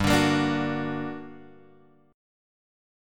G Minor Major 7th